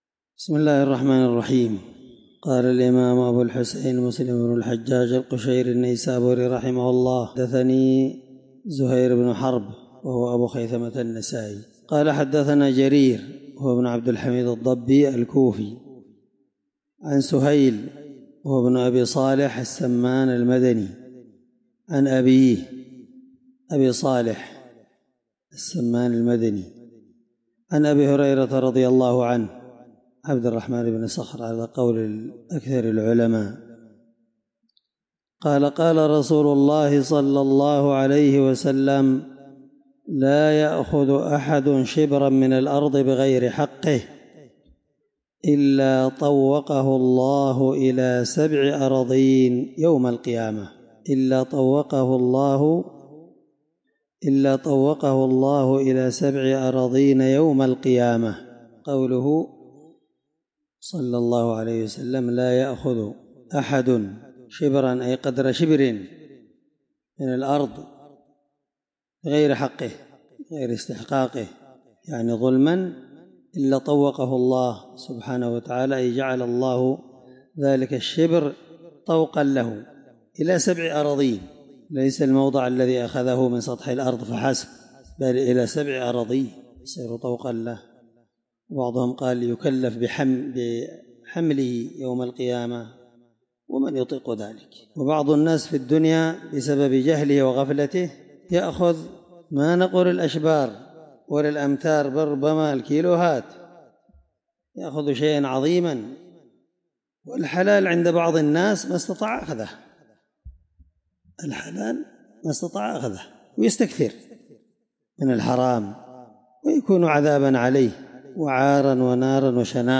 الدرس37من شرح كتاب المساقاة حديث رقم(1611-1612) من صحيح مسلم